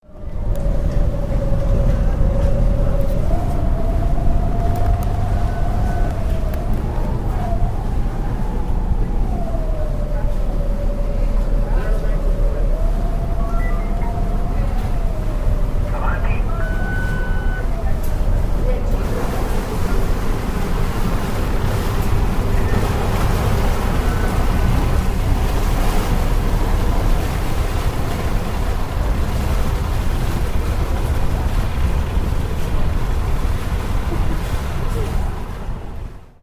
Recording of a vaporetto journey in Venice from 2010 - recorded by Cities and Memory.